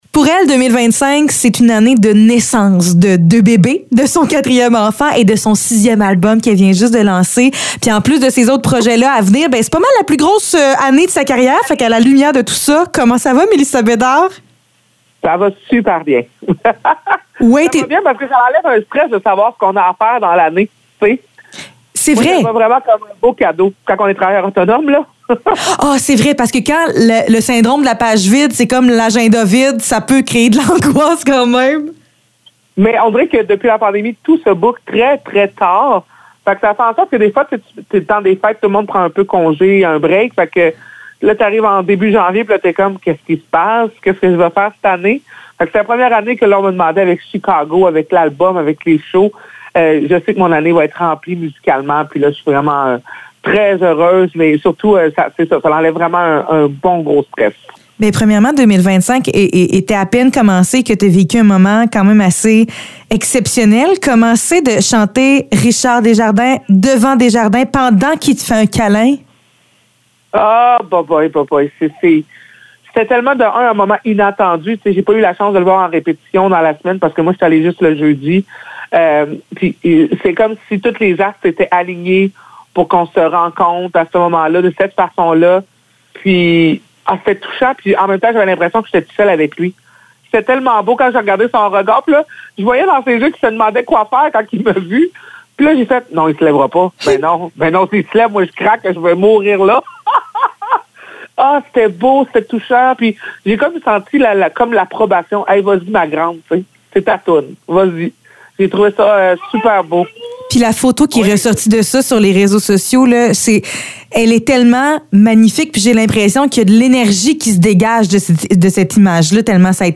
Entrevue avec Mélissa Bédard